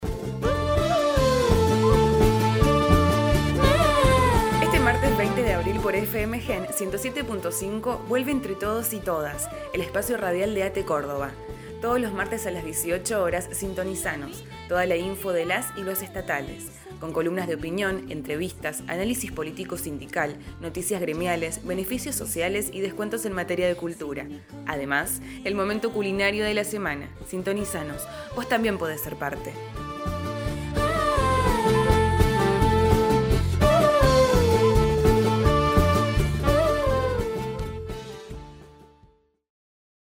Spot-Entre-Todos-y-Todas.mp3